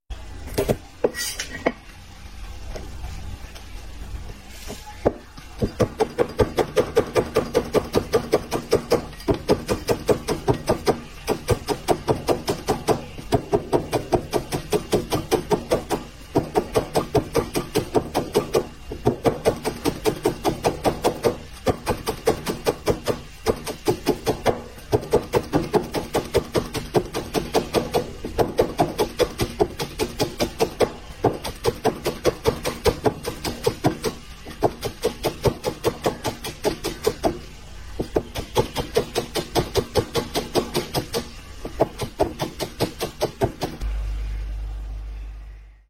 Tiếng thái Lá Chanh đều đặn…
Thể loại: Tiếng ăn uống
Description: Tiếng thái lá chanh đều đặn, sắc sảo và chuyên nghiệp vang lên như một bản nhạc nền tinh tế trong quá trình làm thịt gà. Âm thanh ‘xoẹt xoẹt’ giòn tan của lưỡi dao lướt qua từng lớp lá mỏng tạo nên hiệu ứng âm thanh (sound effect) chân thực, lý tưởng cho việc dựng video nấu ăn. Mỗi nhịp dao – gọn, dứt khoát và chính xác – không chỉ thể hiện kỹ năng mà còn mang đến cảm giác thư giãn, cuốn hút người xem.
tieng-thai-la-chanh-deu-dan-www_tiengdong_com.mp3